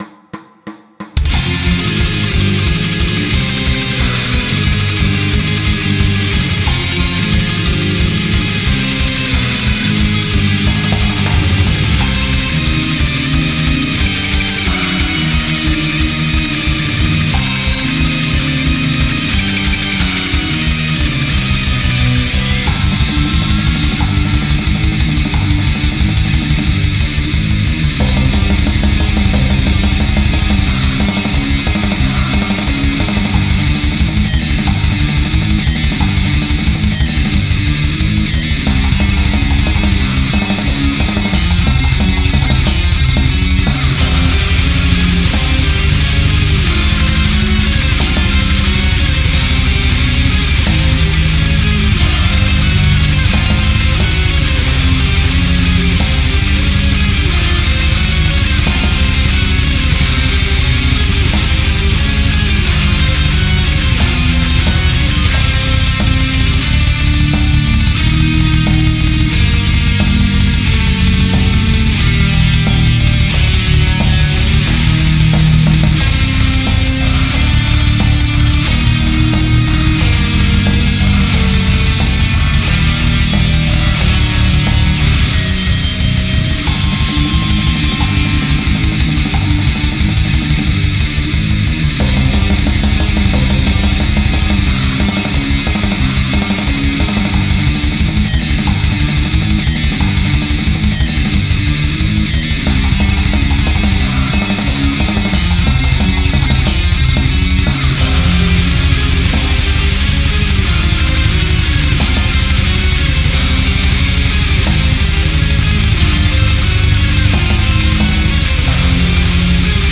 Гитары
Бас-гитара, программирование ударных